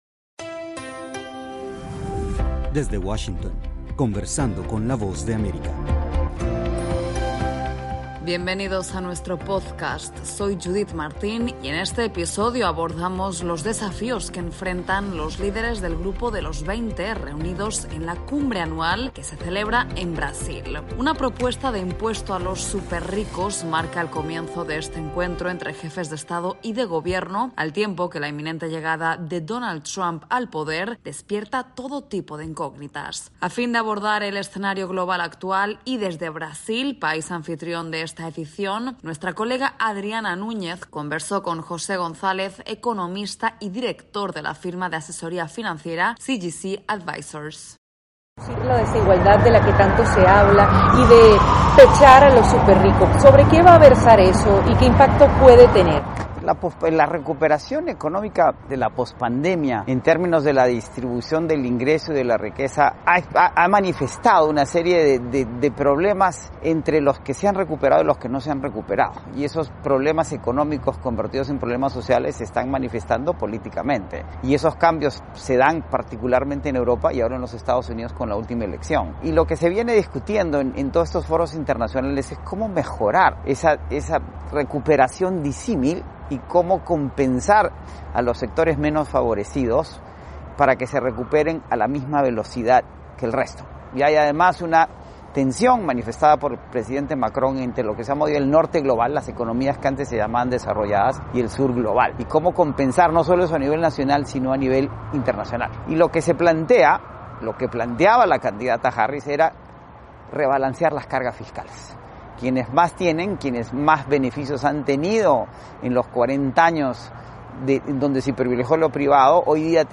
El inminente regreso de Donald Trump a la Casa Blanca ha sacudido los cimientos del escenario internacional. Para analizar este panorama, desde Brasil nuestra colega